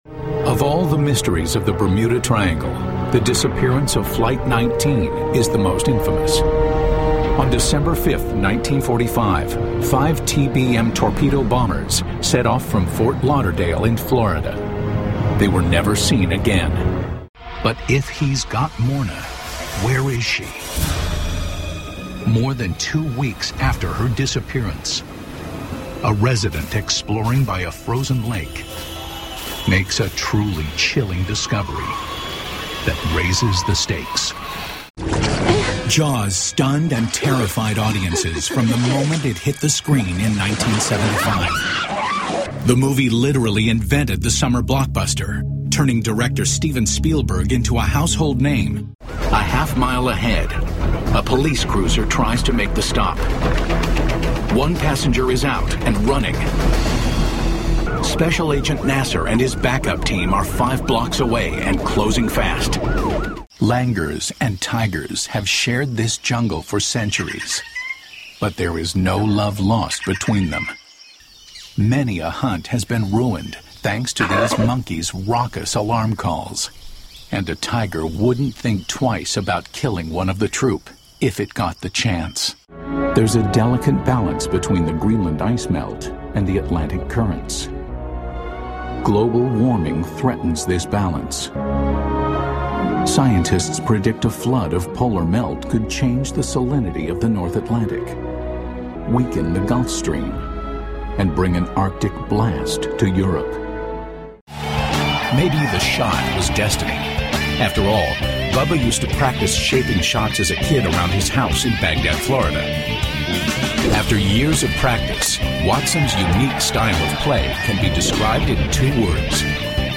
Voice Actor